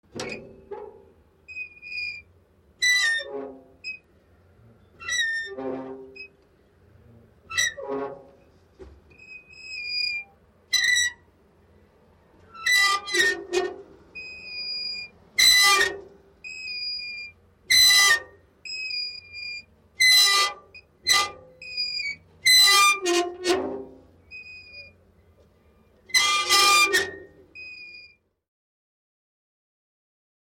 Звук скрипящих шарниров почтового ящика для саунд-дизайна